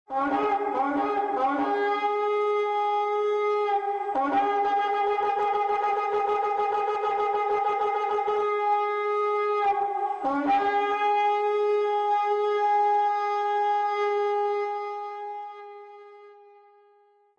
• lo shofar (nell'immagine e nell'audio), ricavato da un corno di montone e utilizzato anche come strumento di richiamo.
shofar.mp3